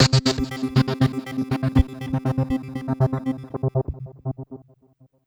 SYNTH CLO0EL.wav